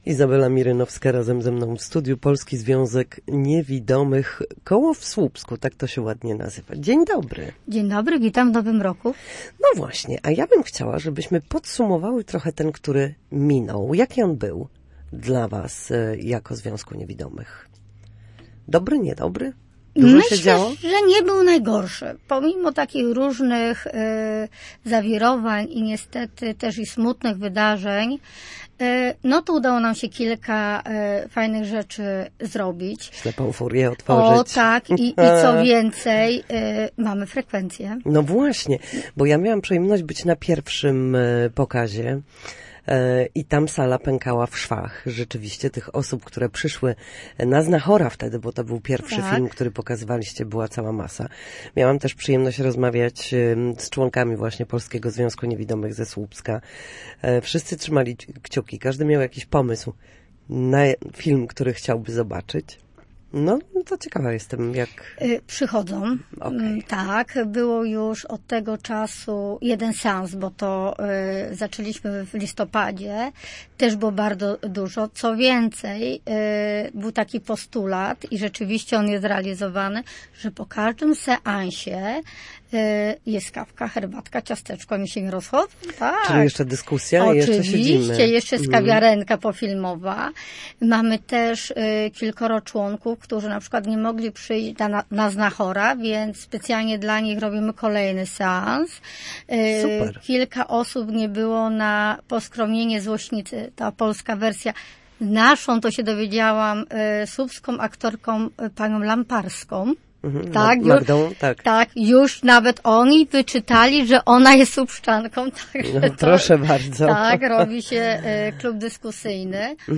Na naszej antenie mówiła o bolączkach i problemach, z którymi na co dzień borykają się osoby z uszkodzonym narządem wzroku.